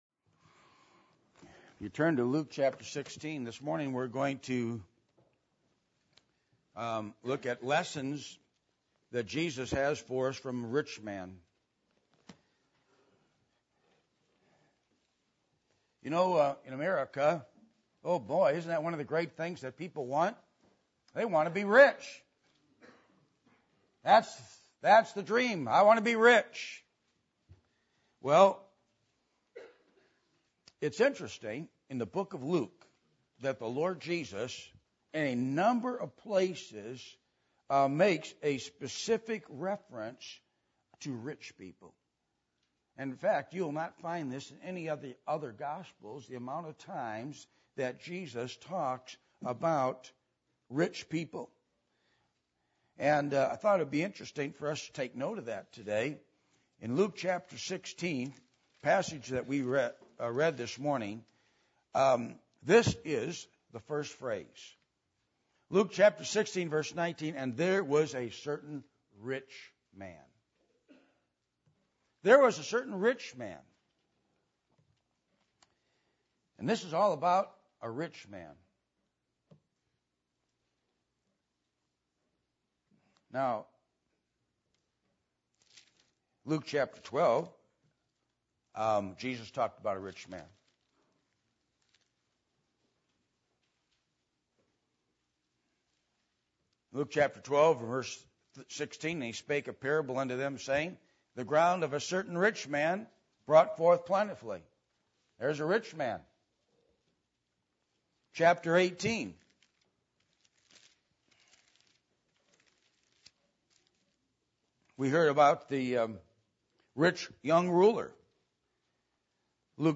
Luke 16:19-31 Service Type: Sunday Morning %todo_render% « The Key Ingredient To Every Endeavor Will You Serve The Lord?